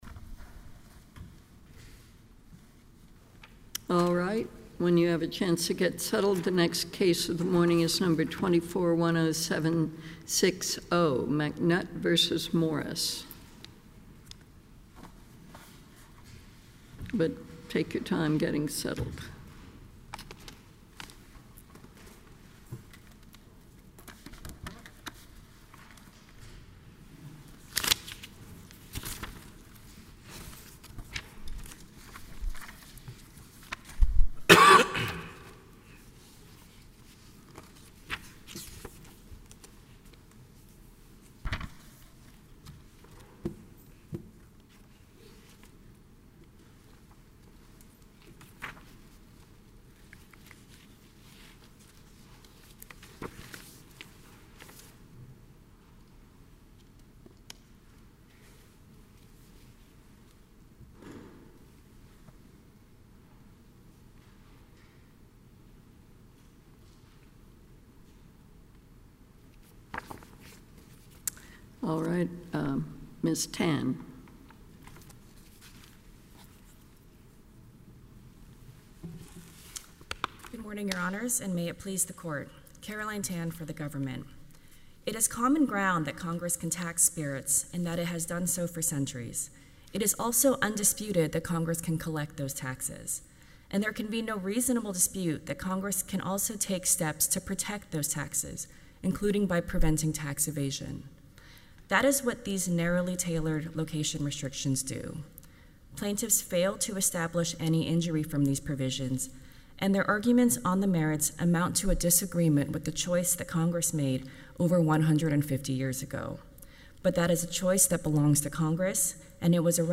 Appeal Heard by the Fifth Circuit
The arguments for the lawsuit regarding hobby distilling were heard by the Fifth Circuit Court of Appeals on August 4, 2025.